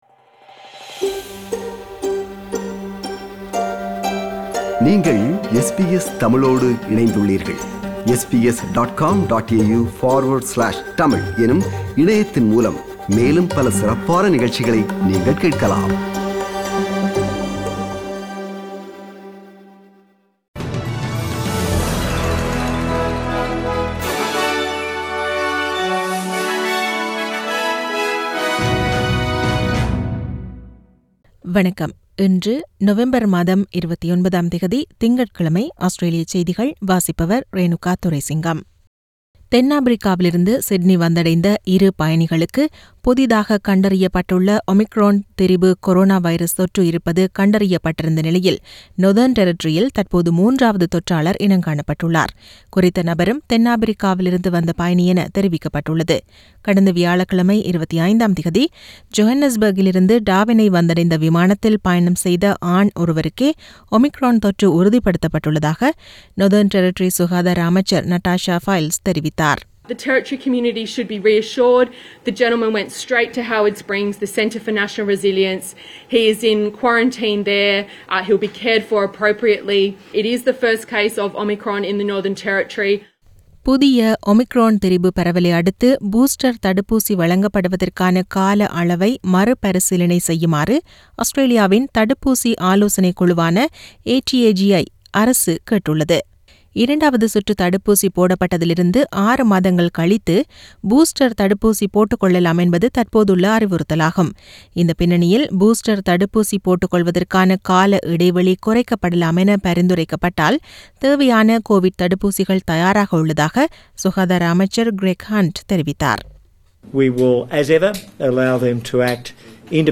Australian news bulletin for Monday 29 Nov 2021.